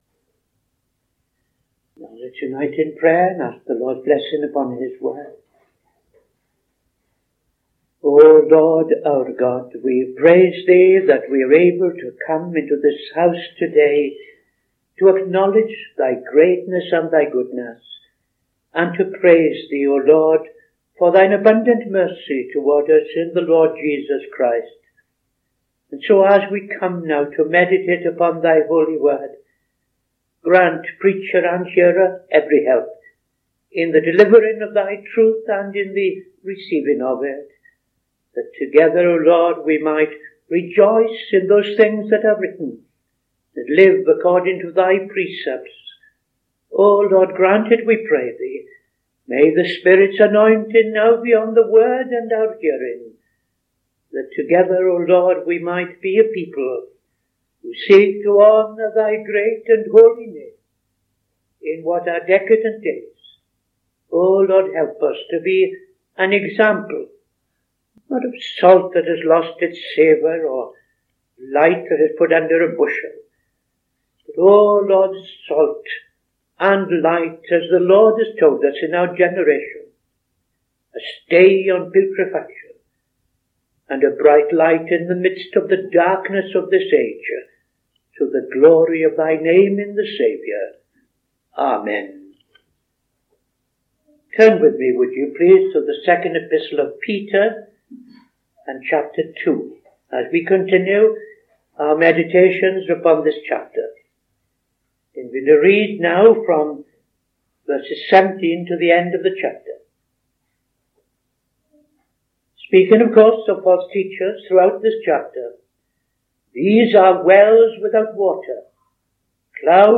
Saturday Sermon - TFCChurch
Opening Prayer and Reading II Peter 2:17-22